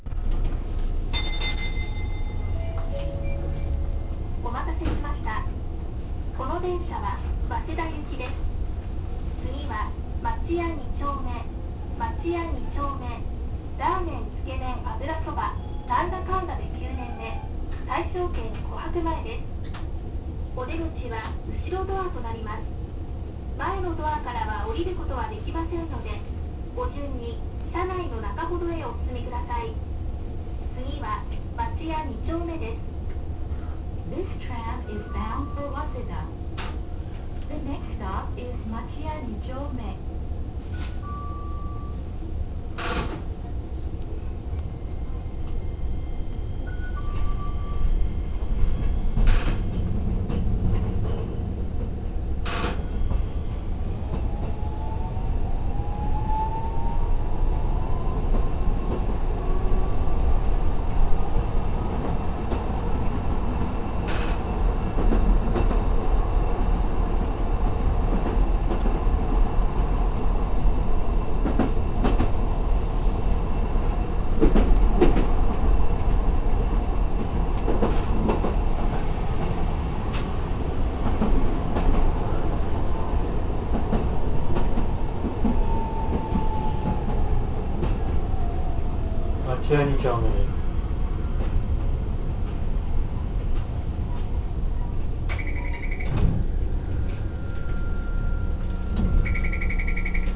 〜車両の音〜
・8900形走行音
【都電荒川線】町屋駅前→町屋二丁目（1分40秒：546KB）
8800形や9000形と走行音自体は変わりません。